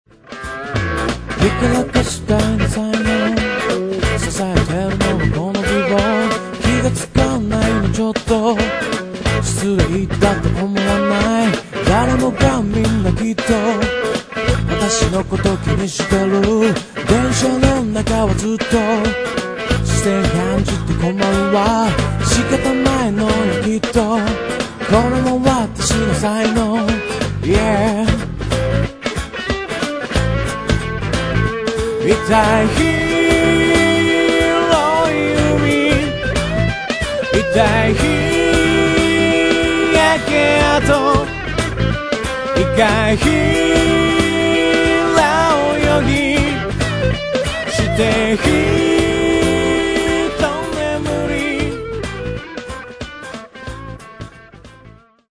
一貫性がないように見えて、何故かひとつの雰囲気に収まっているあたりが不思議なところ。